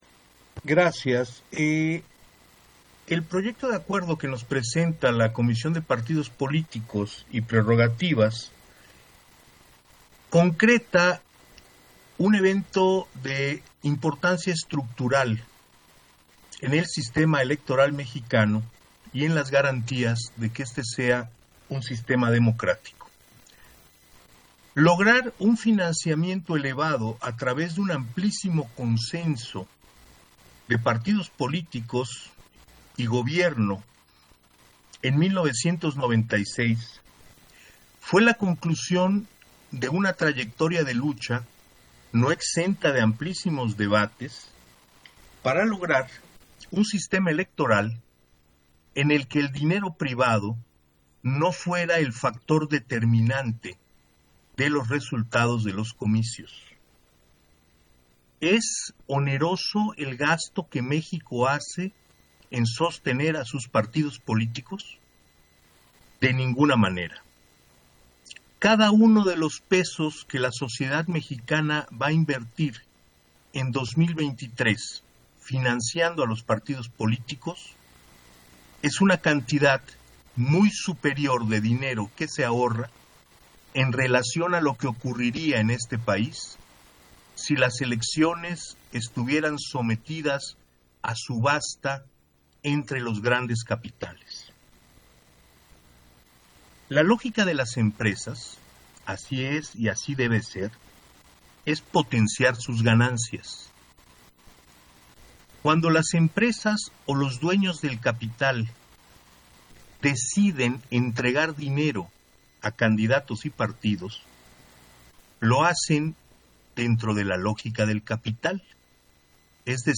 100822_AUDIO_INTERVENCIÓN-CONSEJERO-ESPADAS-PUNTO-2-SESIÓN-EXT. - Central Electoral